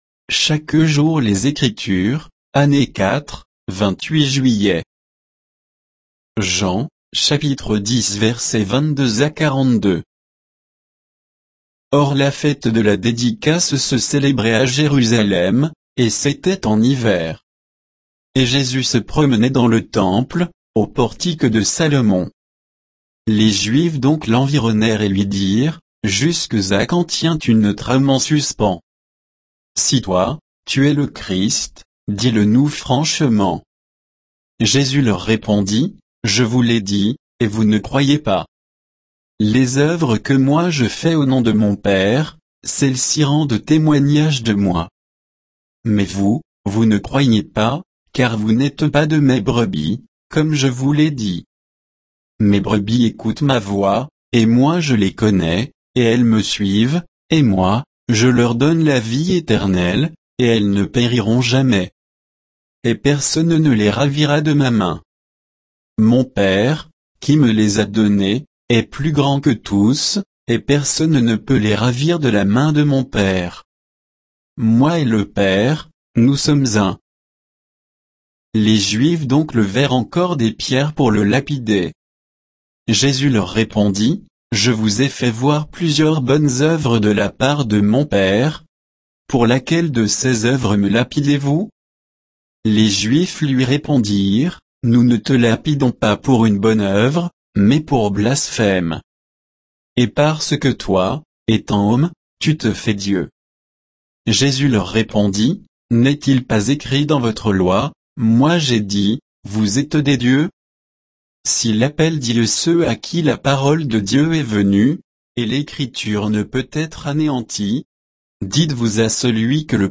Méditation quoditienne de Chaque jour les Écritures sur Jean 10